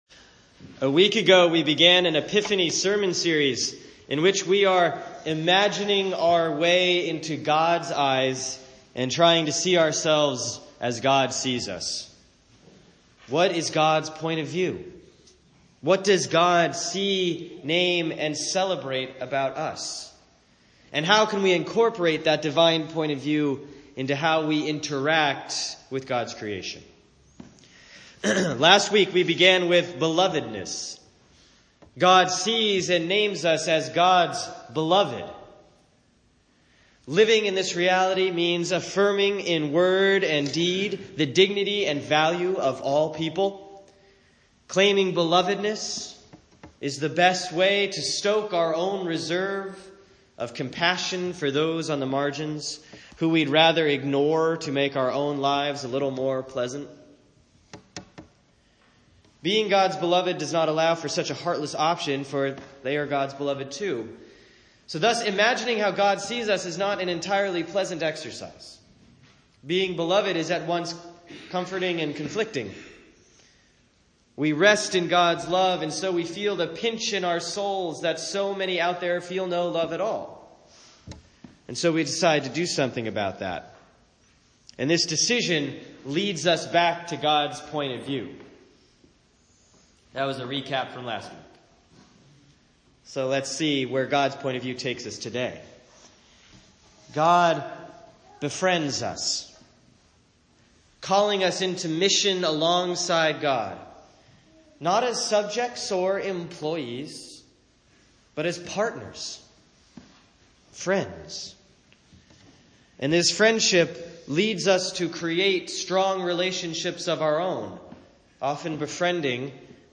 Week two of the Epiphany sermon series on what God sees, names, and celebrates about us. This week: God names us Befriended.